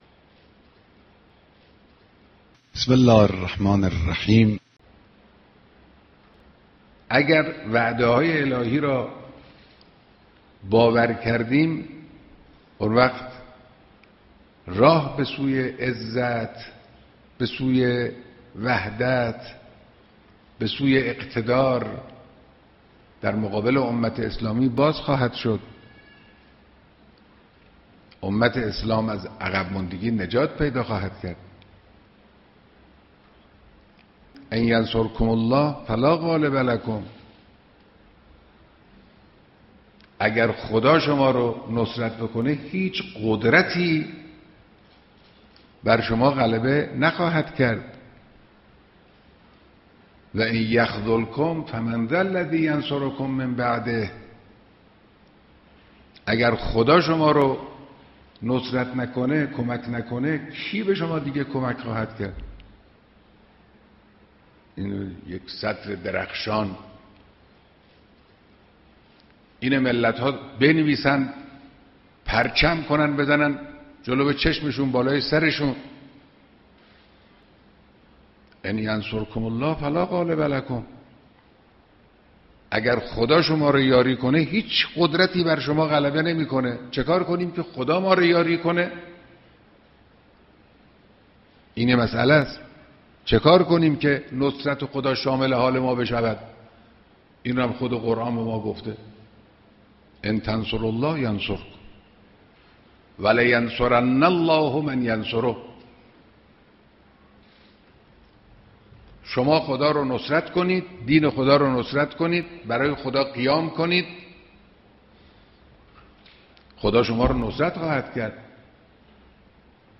صوت جملات امام خامنه ای, صوت بیانات رهبر